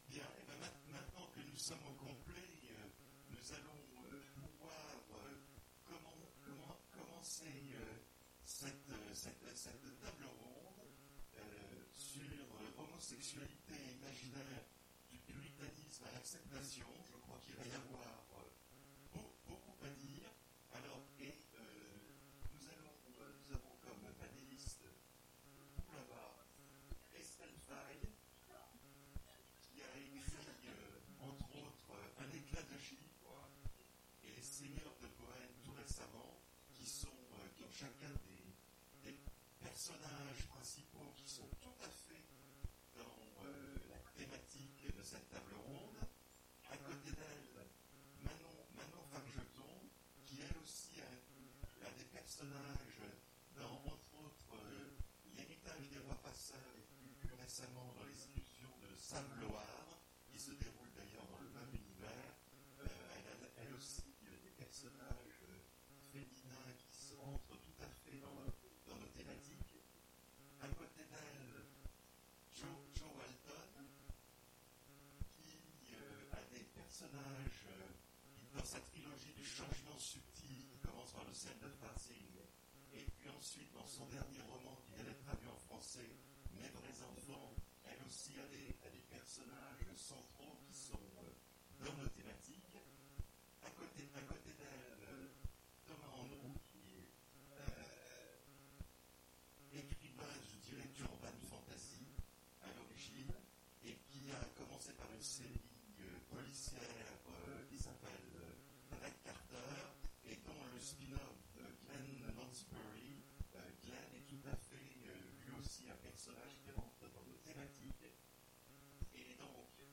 Imaginales 2017 : Conférence Homosexualité & imaginaires, du pluralisme à l'acceptation ?